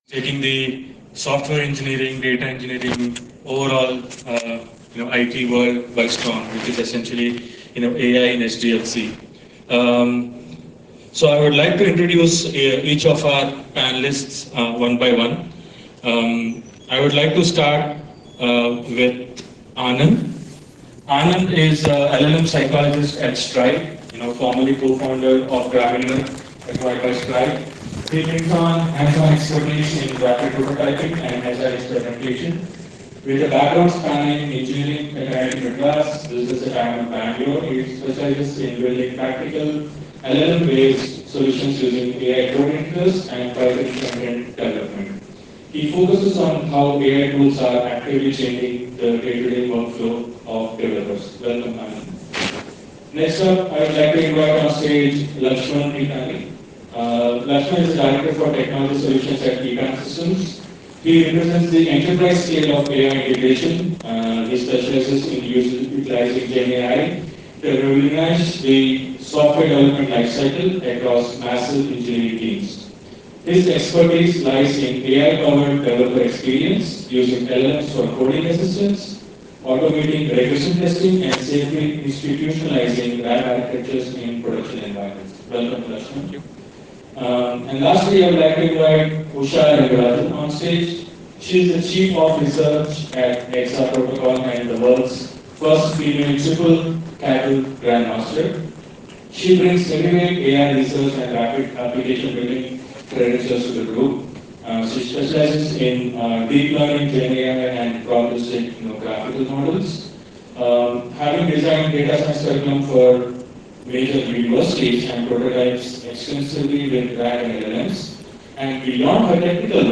PyConf Hyderabad 2026 · AI in SDLC Panel Discussion
Three AI practitioners walked onto a stage in Hyderabad with very different answers to a question nobody could quite agree on.